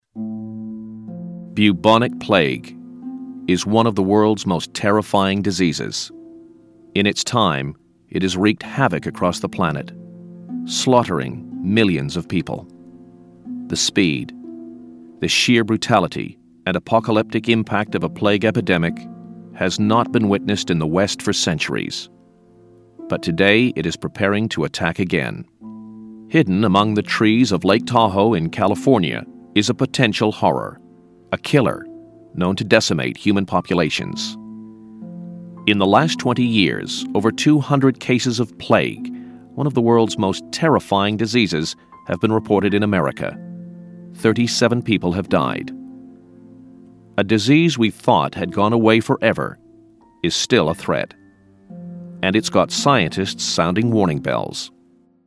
Male, American, 40s, 59s, 60s, American, DJ, The Kid, Documentary, commercial, advert, voiceover, voice over, DGV, Damn Good Voices, damngoodvoices, Crying Out Loud, cryingoutloud,